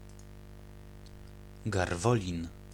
Garwolin [ɡarˈvɔlʲin]
Pl-Garwolin.ogg.mp3